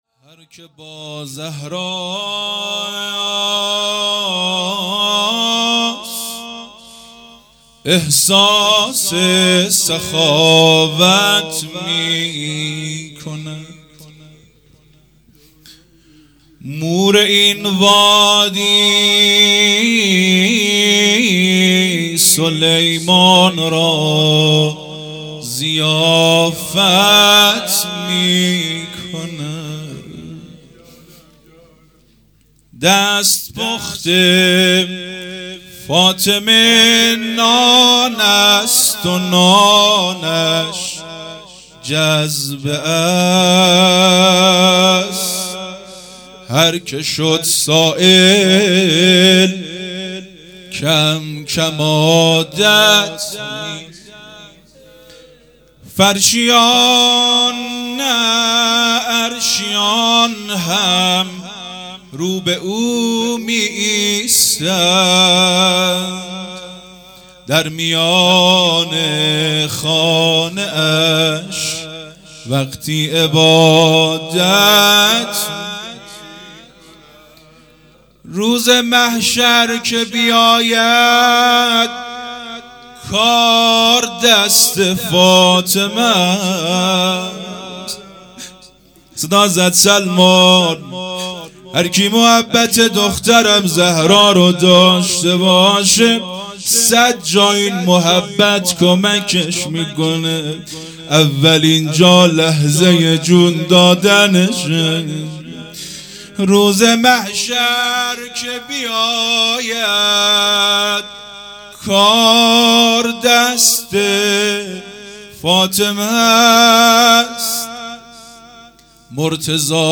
هیئت مکتب الزهرا(س)دارالعباده یزد
روضه | هرکه با زهراست احساس سخاوت میکند